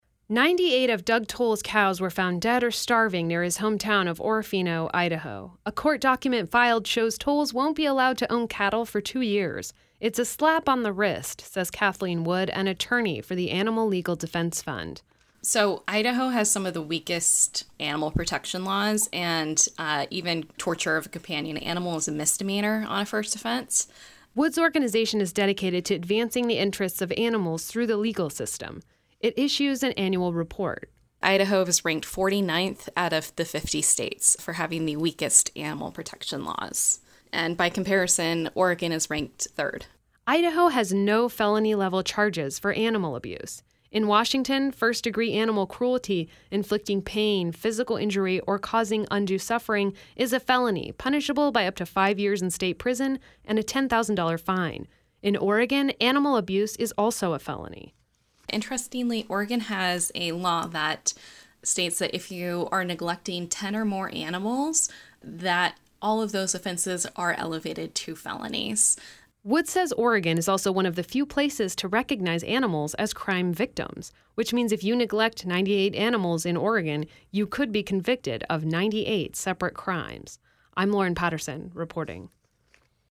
A north Idaho court has levied $1200 in court costs to a cattle rancher who pleaded guilty to charges of animal abuse. It’s a lenient sentence if you consider the punishment in Oregon or Washington. Correspondent